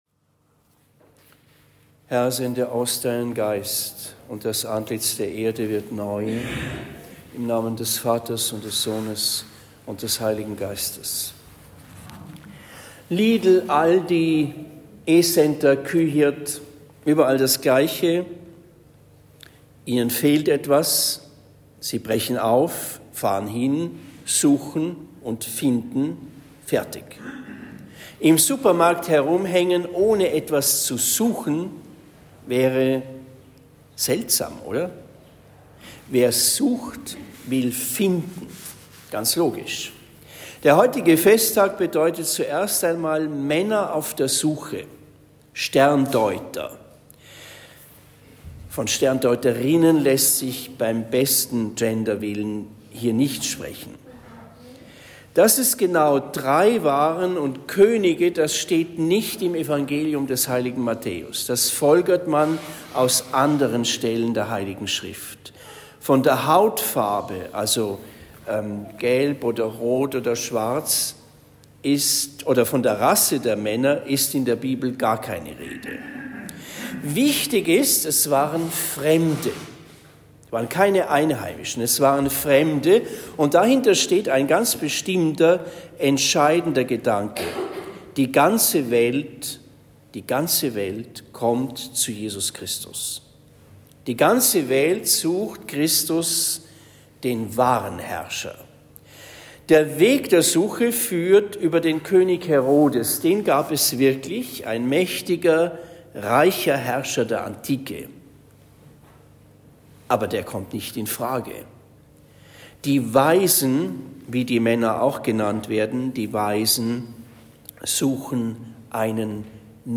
Hochfest Epiphanie Predigt am 06. Jänner 2024 in Marienbrunn St.-Barbara